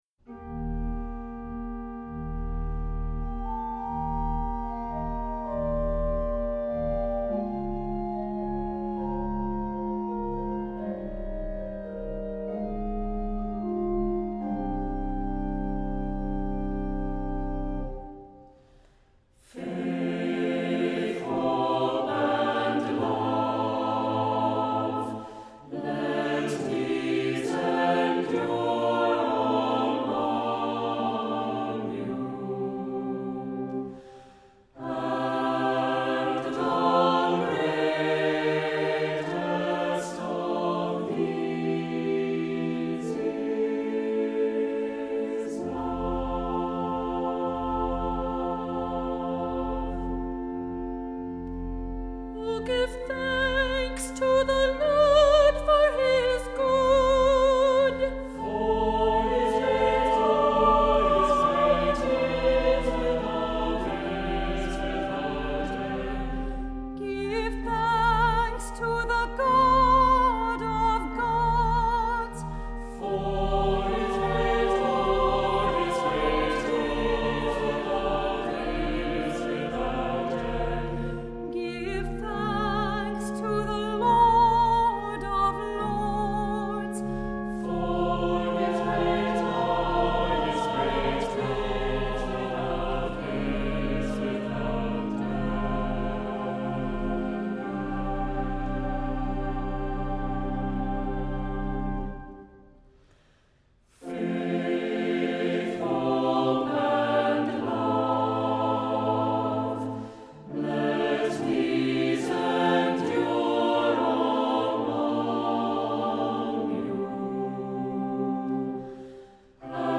Accompaniment:      Organ
Music Category:      Christian